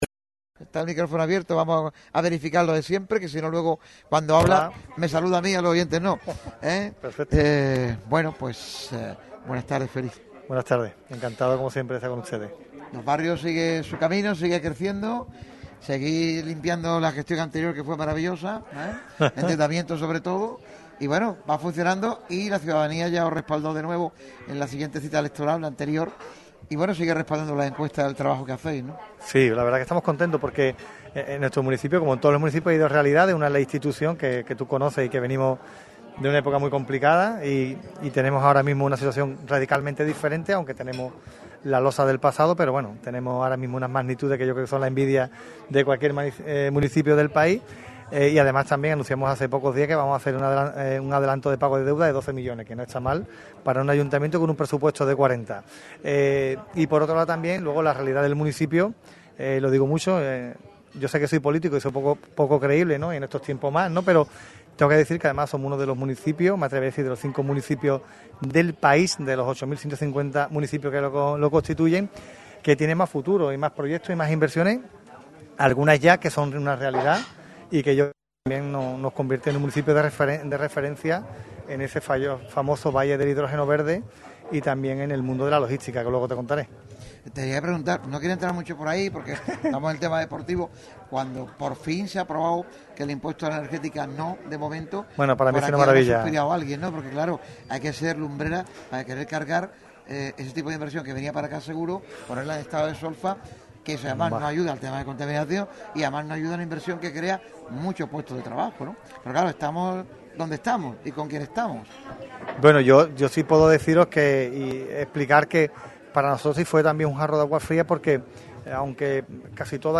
El edil ha estado presente en el programa especial que Radio MARCA Málaga ha realizado desde el restaurante Casa Mané situado en la playa Palmones de Algeciras
Miguel Fermín Alconchel, alcalde de Los Barrios, ha pasado por los micrófonos de Radio MARCA Málaga.
ENT-ALCALDE.mp3